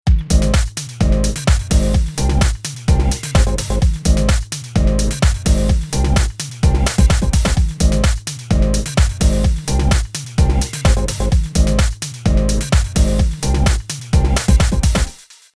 Basic loop treated with multi-band compression